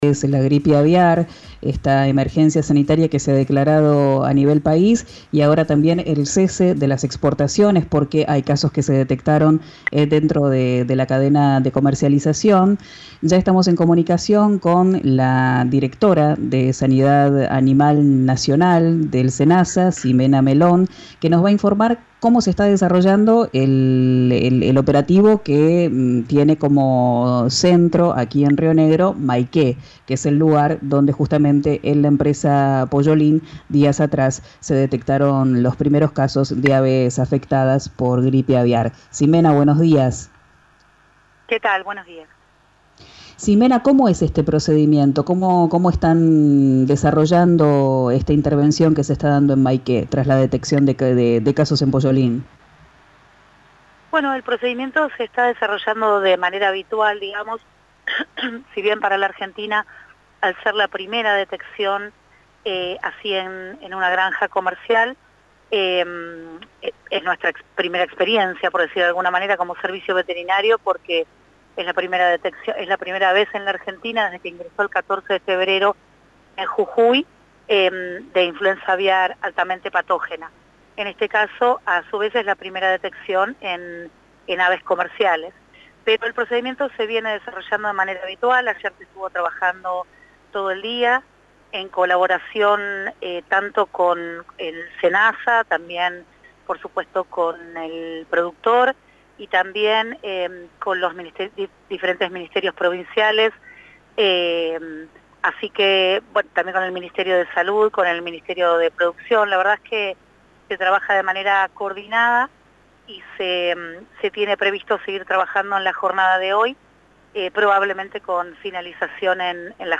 Senasa prevé finalizar el 'vaciamiento sanitario' hoy. En diálogo con RÍO NEGRO RADIO, la directora nacional de Sanidad Animal, Ximena Melón, destacó que el procedimiento es 'fundamental' para que el virus no se propague.